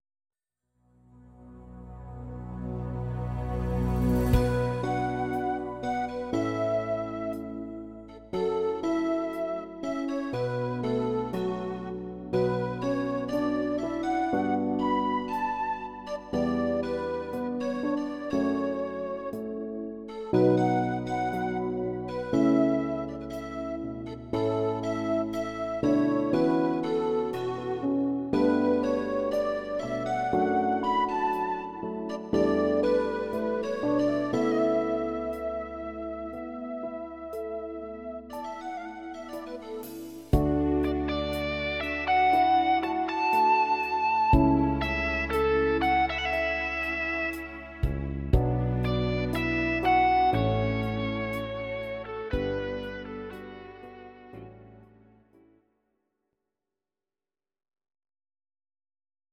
Audio Recordings based on Midi-files
Pop, Musical/Film/TV, Duets, 1990s